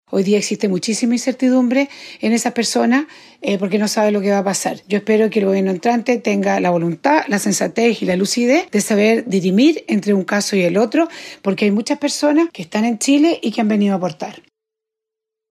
Sobre esto, la diputada de Renovación Nacional, Ximena Ossandón, remarcó que el próximo gobierno debe saber dirimir entre un caso y otro, ya que cada situación es diferente.